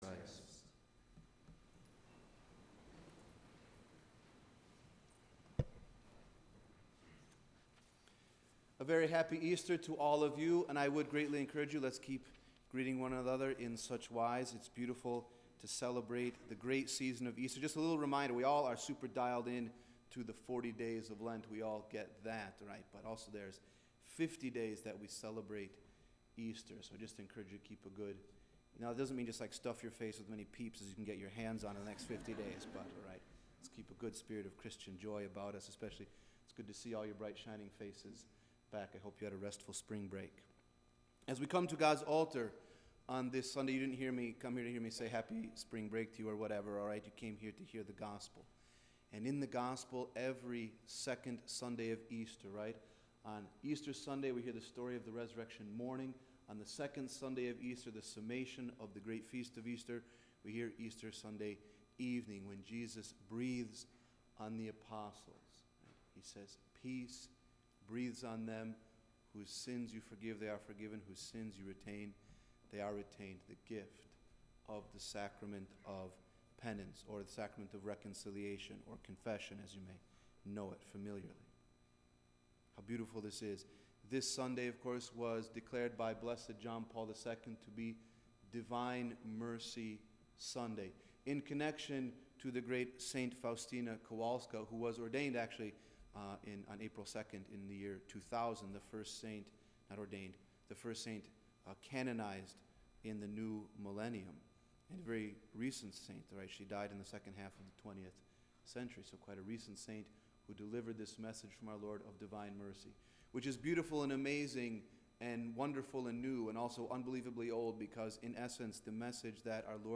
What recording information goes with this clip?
As preached in the chapel of St. Paul, Madison, WI at the 9:00 am Mass